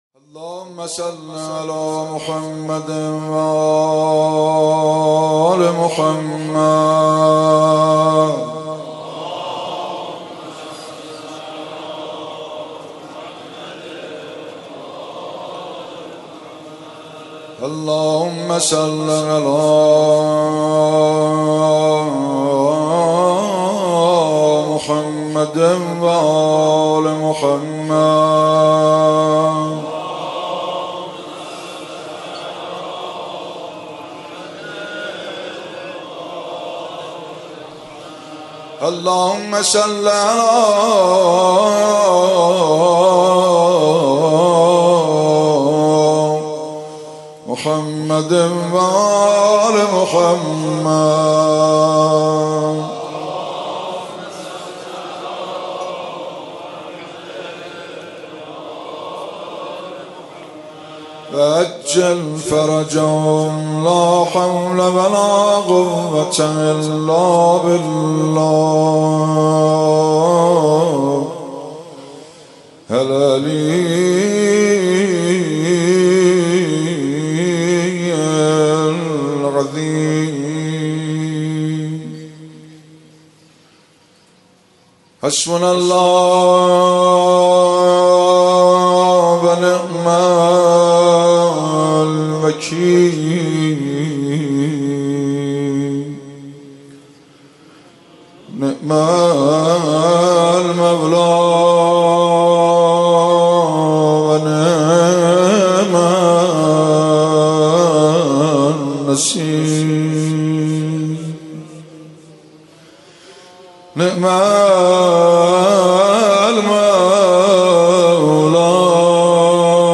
مناجات
روضه